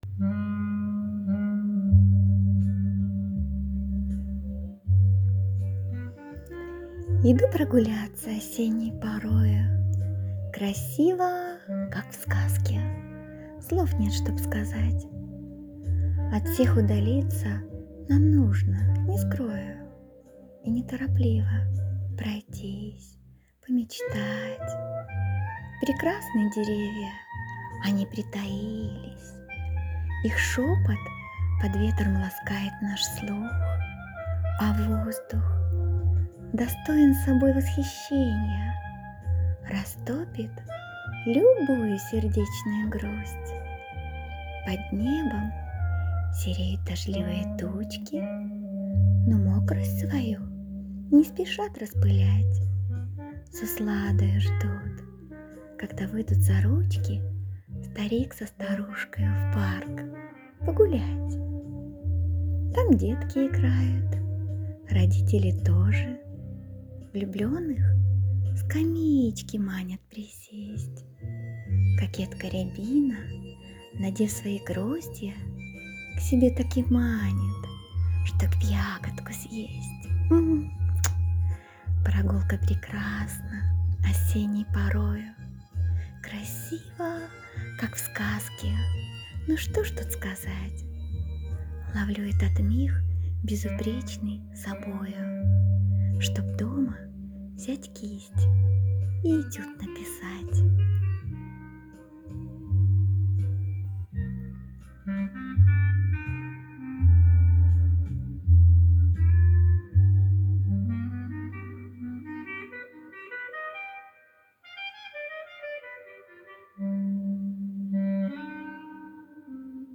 🍂🍁🍃🌿🍁🍂 ОСЕННИЙ ЭТЮД… ( стих начитан)
ВИД ТВОРУ: Вірш